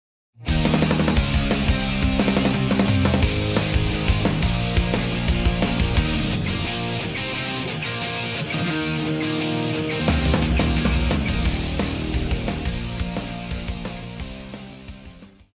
套鼓(架子鼓)
乐团
演奏曲
朋克,流行音乐
独奏与伴奏
有主奏
有节拍器